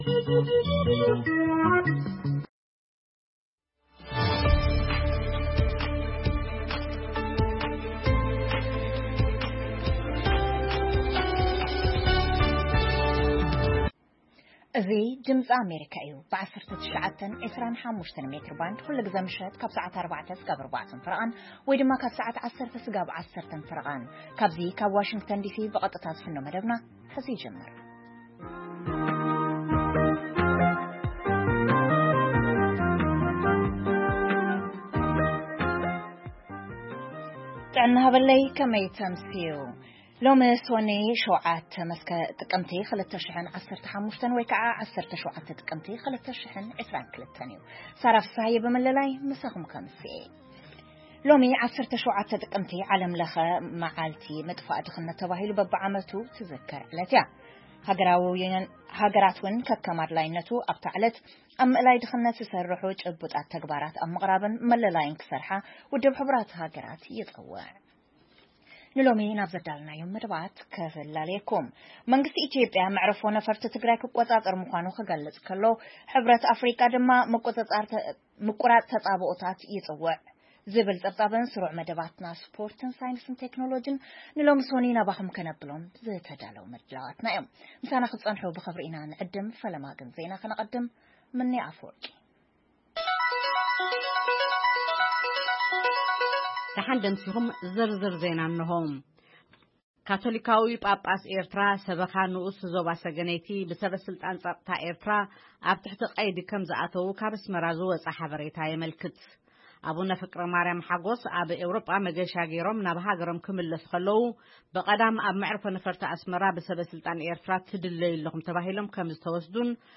ፈነወ ድምጺ ኣመሪካ ቋንቋ ትግርኛ ጥቅምቲ 17,2022 ዜና (መግለጺ ዋና ጸሓፊ ሕ/ሃ ኣብ ጉዳይ ኢትዮጵያ፥ ካቶሊካዊ ጳጳስ ኤርትራ ሰበኻ ንኡስ ዞባ ሰገነይቲ ብሰበ-ስልጣን ጸጥታ ኣብ ትሕቲ ቀይዲ ከምዝኣተዉ ካብ ኣስመራ ዝወጸ ሓበሬታ የመልክት፥መንግስቲ ኢትዮጵያ መዓርፎ-ነፍርቲ ክልል ትግራይ ከምዝቈጻጸር ይሕብር፣ ) መደብ ሳይንስ ቴክኖሎጂን ፡ 'ካቢ+' ዝተባህለ ንናይ ትግርኛ ፊልምታት ዝፍነው መድረኽ ዘዳለው መንእሰያት ዝተኻየደ ቃለ መጠይቕ (1ይ ክፋል) የጠቓልል